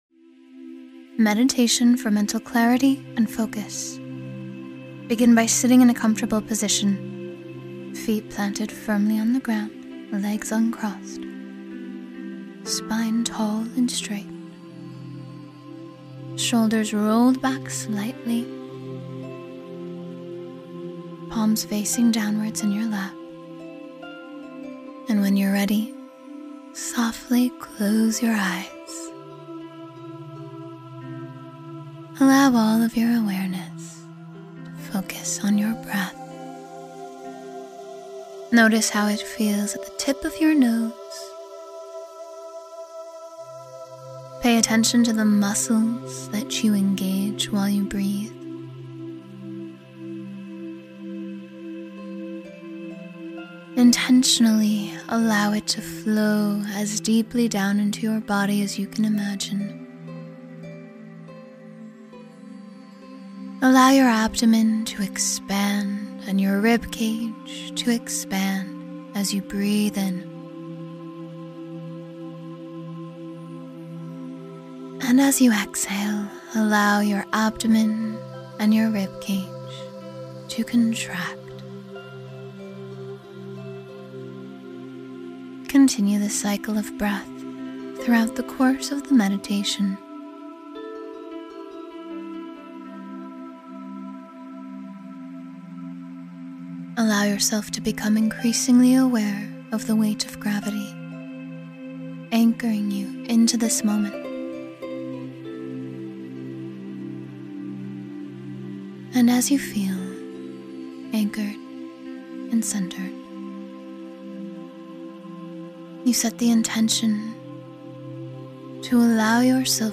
Mental Clarity and Focus — 10-Minute Meditation for Total Calm